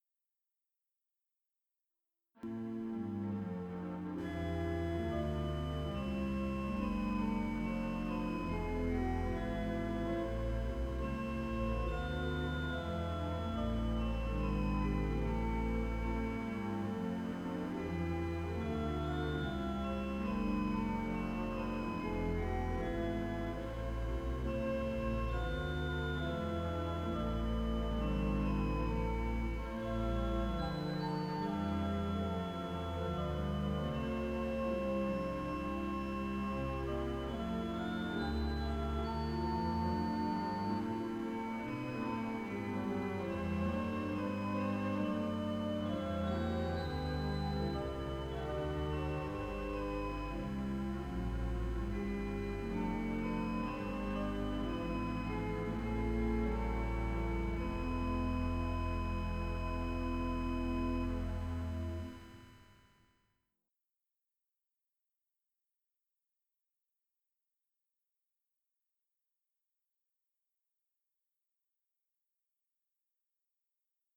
I suggest that the descant is used in one of the middle verses unless you are happy ending in E major rather than A minor.
Passion-Chorale-Descant-Verse.mp3